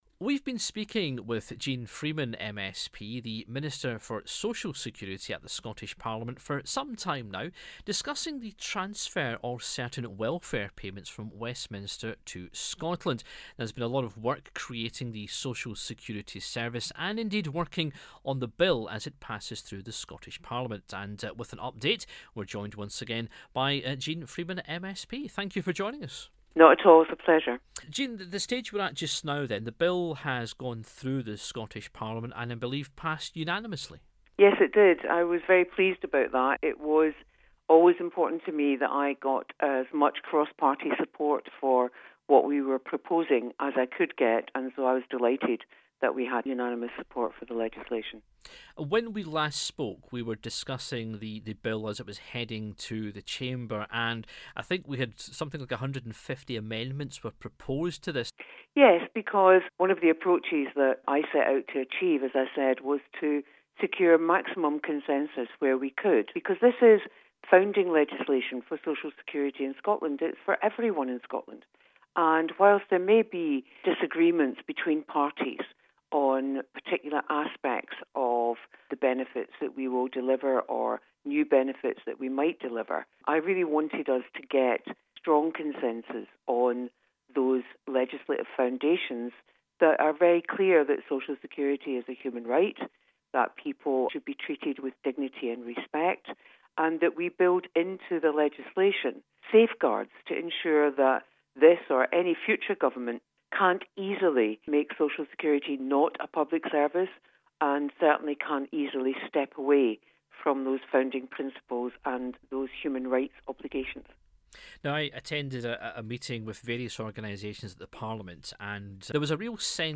spoke to Jeane Freeman MSP, Minister for Social Security, to learn more about the payments, Assessments, appeals and more...